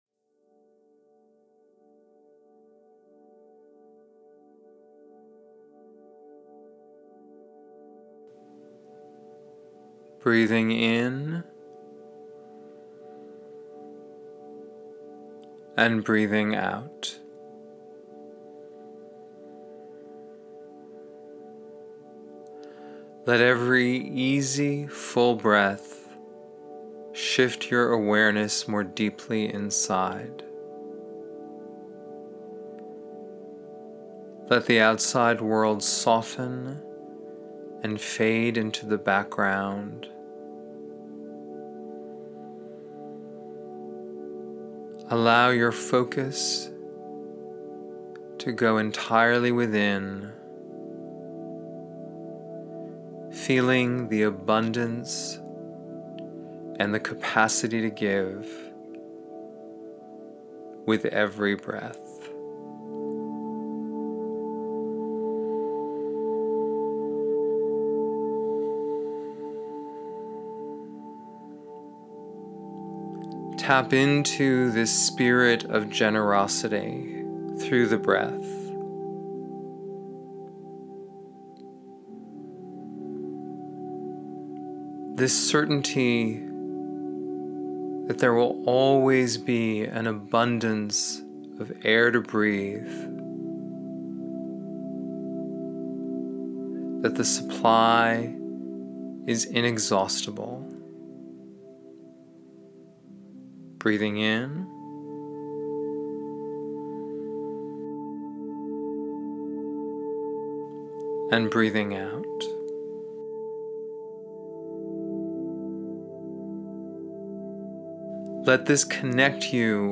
Generosity-Meditation.mp3